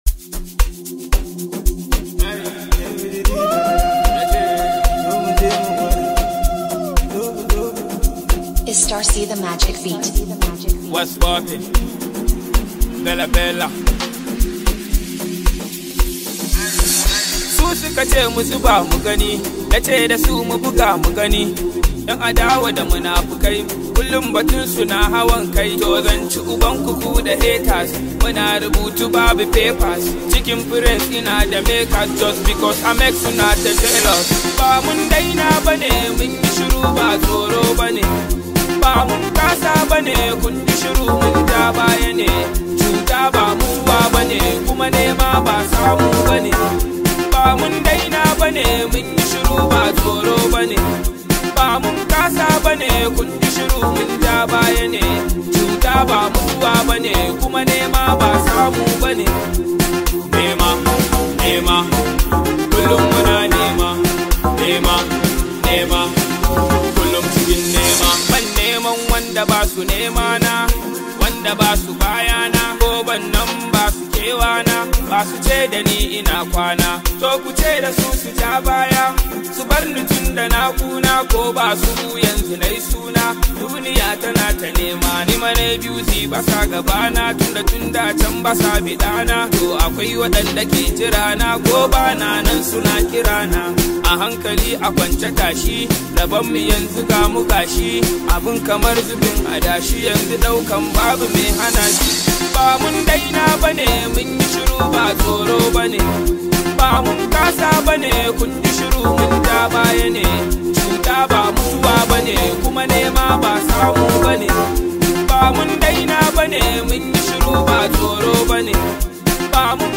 Hausa Singer
it comes with a lot of energy and positive Vibes